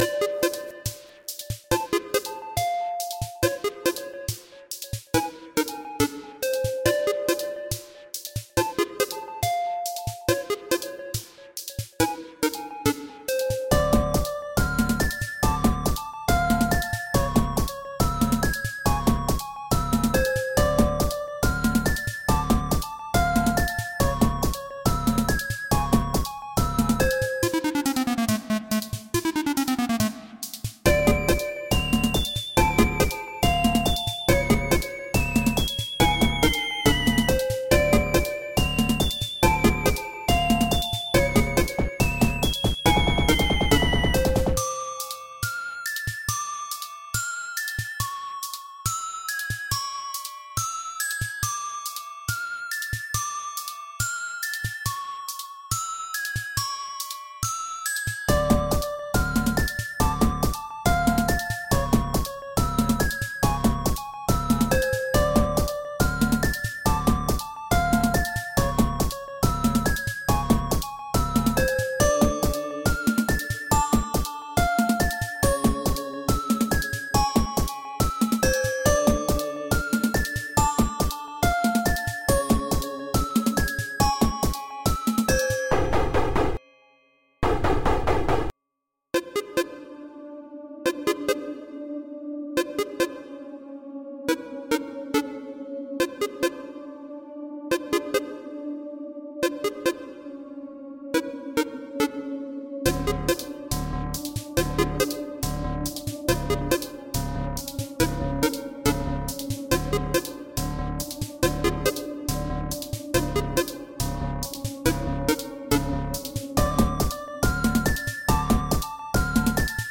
It has a retro feeling to it.